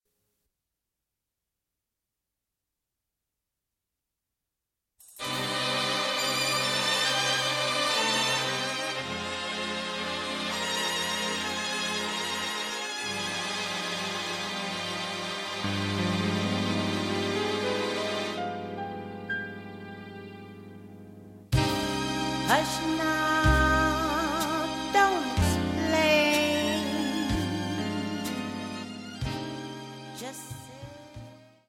Chant et Guitare